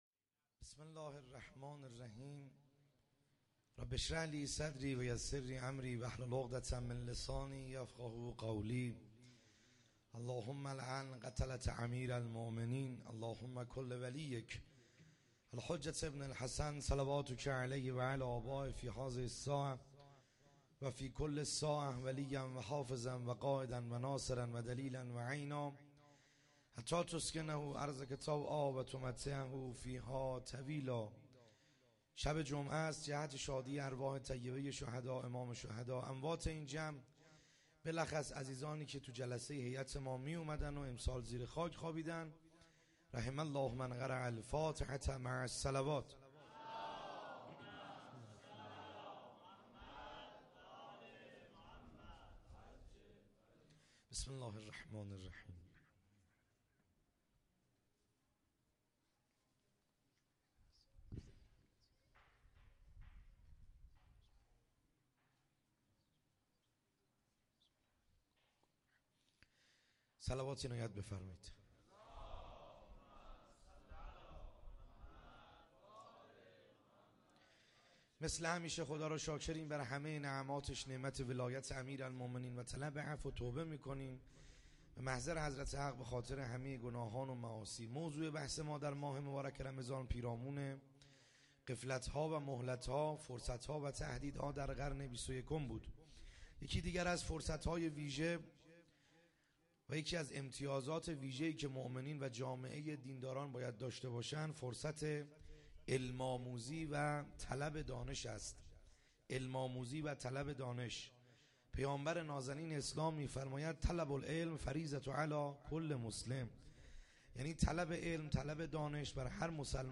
خیمه گاه - بیرق معظم محبین حضرت صاحب الزمان(عج) - سخنرانی